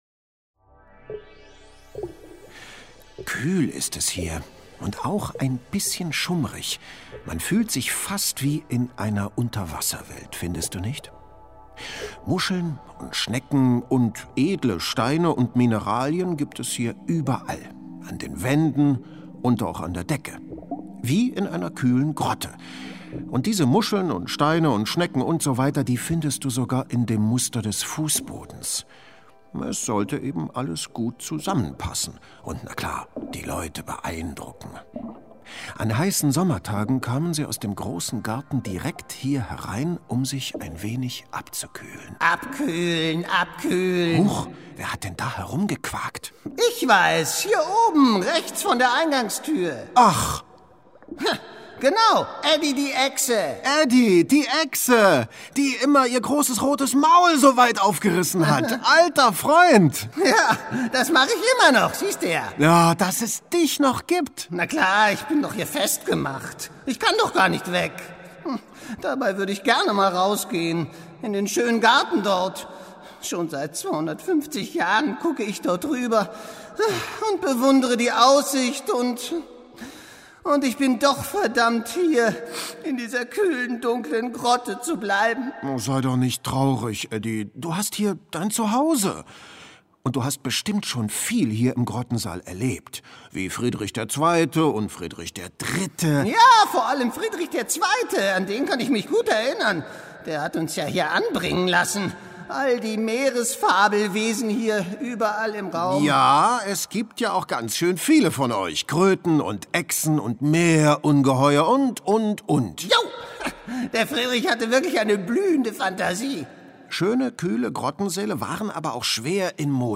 Dabei treffen sie unter anderem Eddie, die Echse im Grottensaal, Kronprinzessin Vicky, die fließendes Wasser und Badewannen ins Schloss einbauen möchte oder in der Jagkammer Schnuff, den faulenzenden Jagdhund. In kurzen, mit Musik und Geräuschen untermalten Hörspielszenen wird die Vergangenheit lebendig, etwa wenn der Sultan Bajazed über seine Gefangenschaft klagt oder Göttervater Zeus mit viel Getöse die Gäste an seiner Festtafel vorstellt.